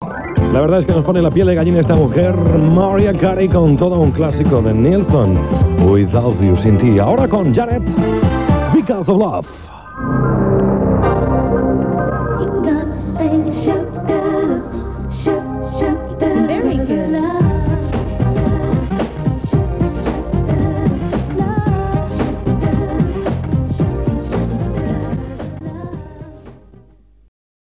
Presentació d'un tema musical
Musical
FM